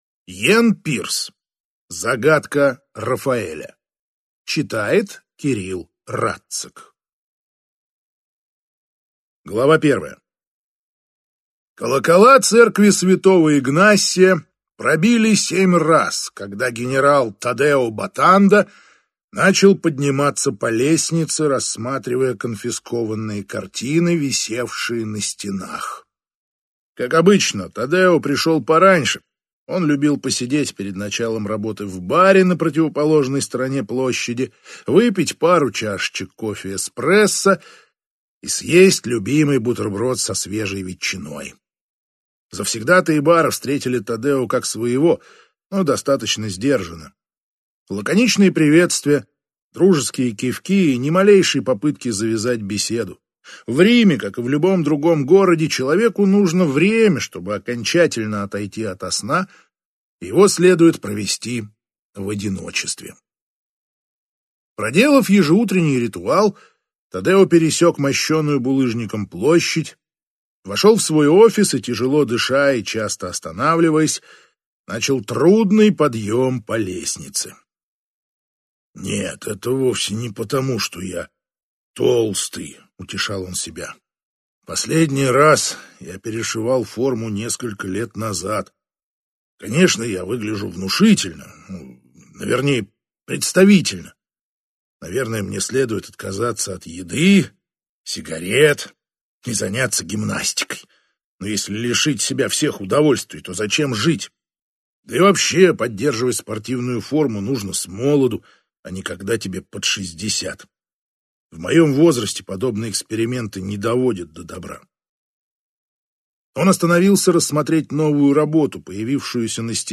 Аудиокнига Загадка Рафаэля | Библиотека аудиокниг